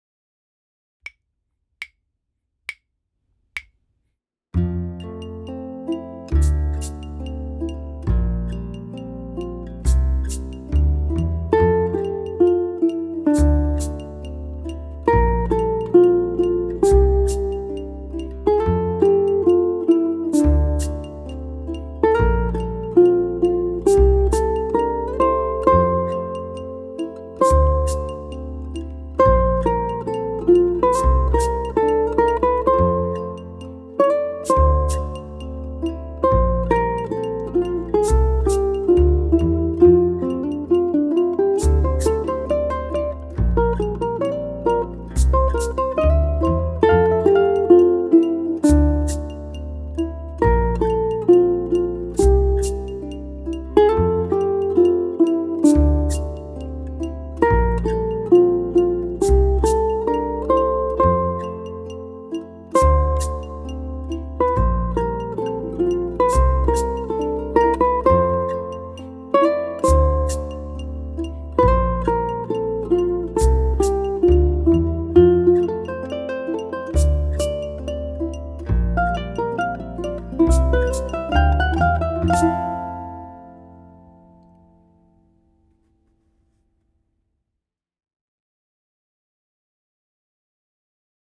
The nostalgic melody floats above the progression in a mix of eighth notes and longer durations.
ʻukulele
Betty Lou of '52 is written in ballad style and sounds best about 76-80 BPM, the slow andante range.